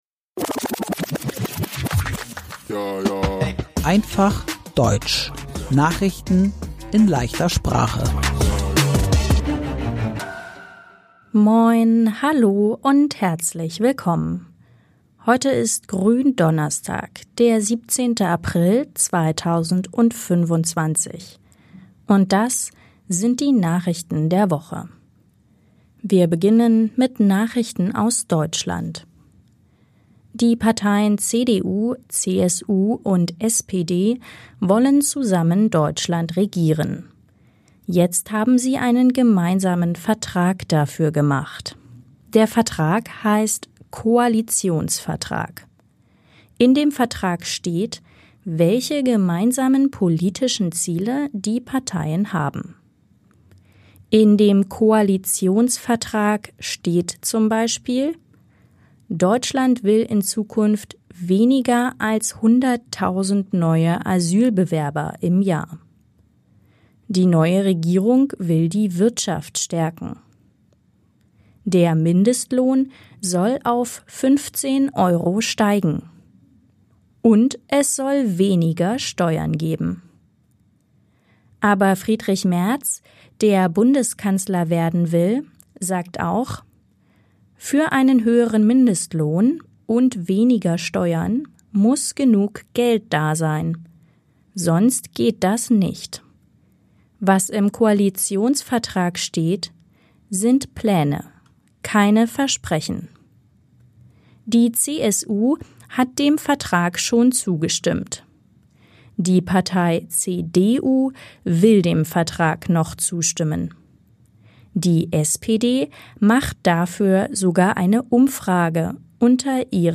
Hamas-Chef Sinwar ist tot – Einfach Deutsch: Nachrichten in leichter Sprache – Lyssna här